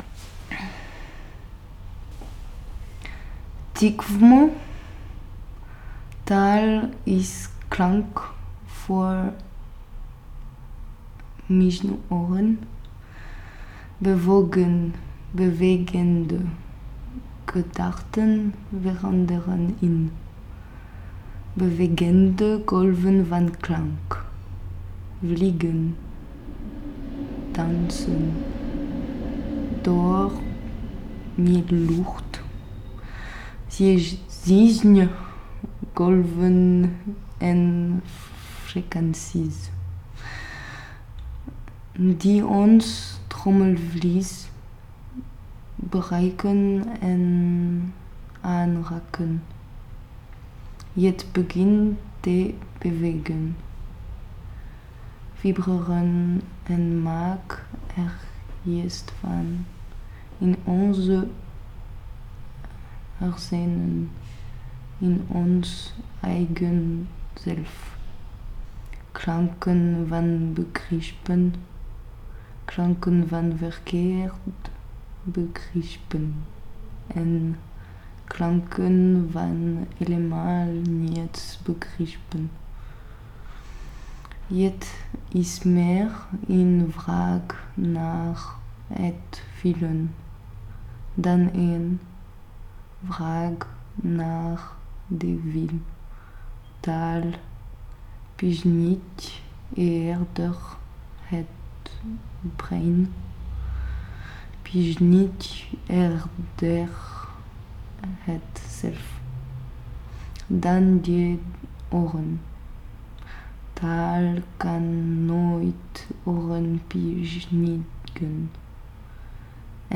(frz. Native)